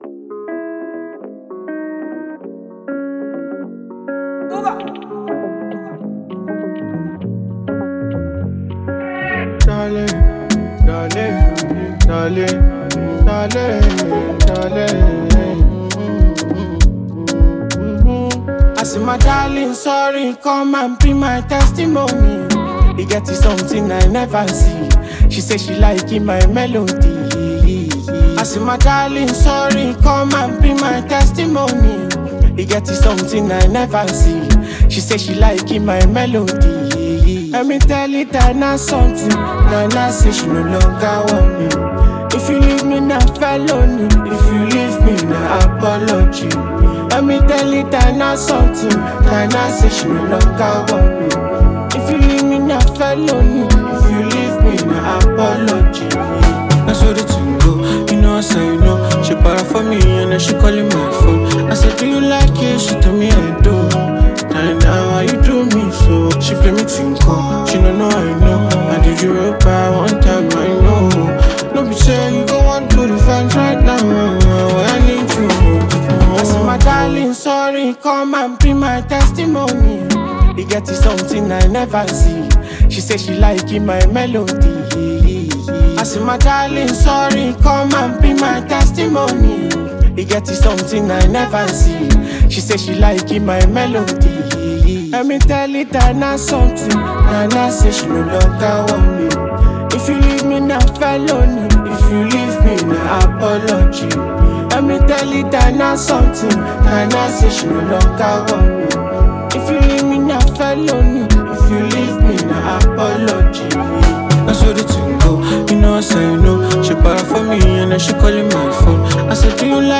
mid-tempo instrumental